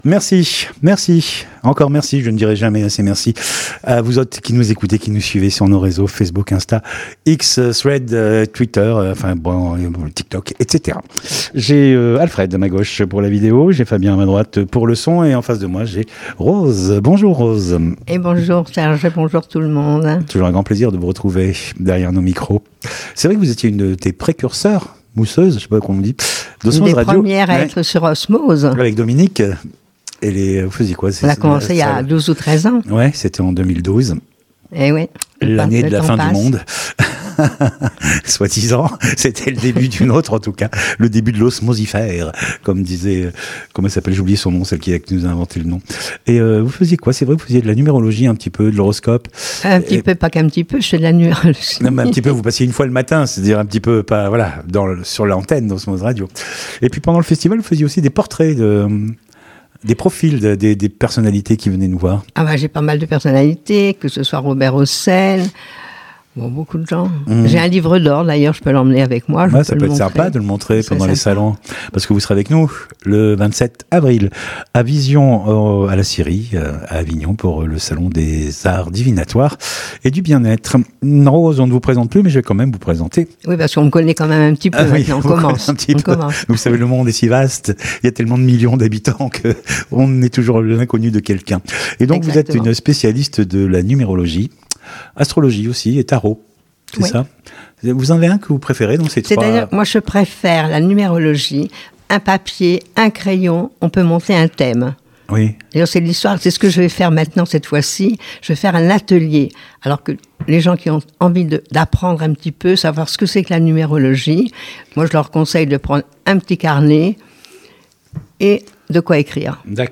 Bien être/Santé Interviews courtes